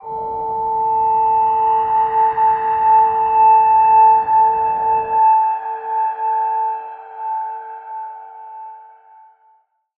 G_Crystal-A5-pp.wav